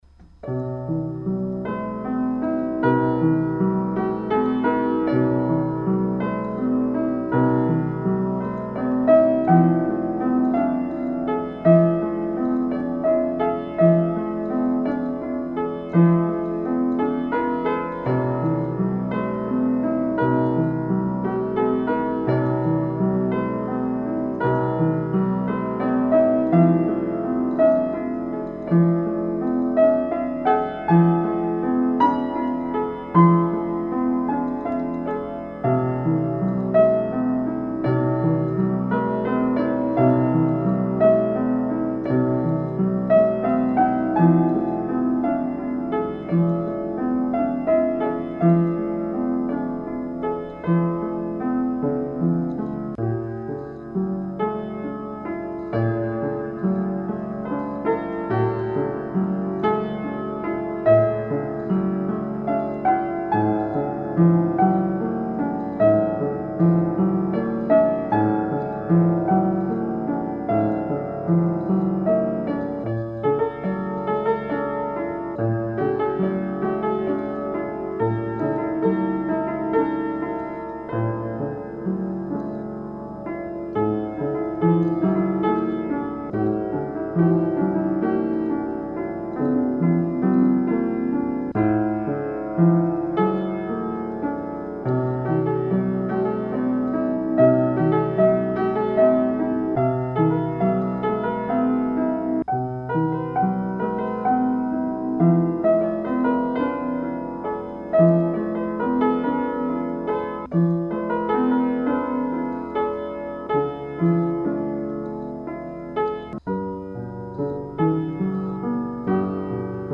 В основном пишу небольшие фортепианные мелодии. Может кому пригодятся. при необходимости запишу в лучшем качестве